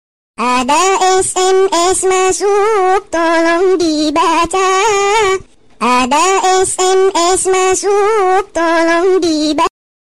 Genre: Nada dering viral TikTok